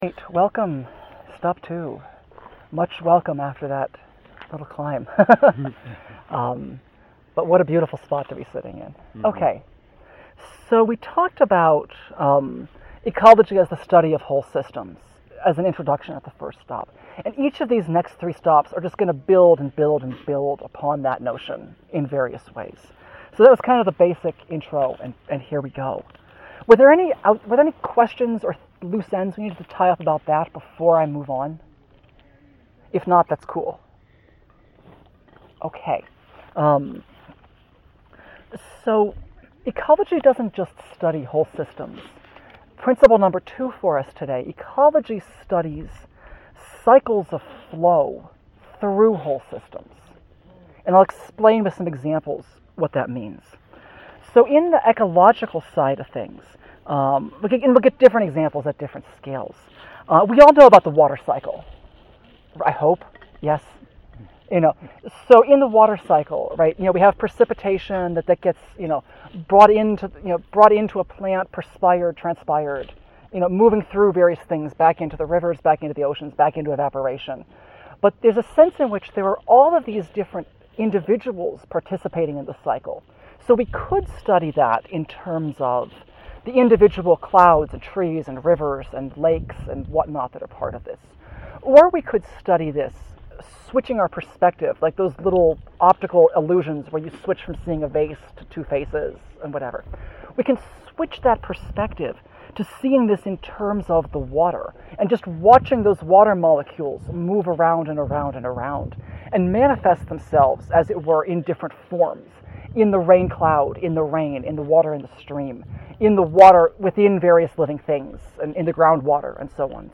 We had a delightful time on our September Philosophy Walk.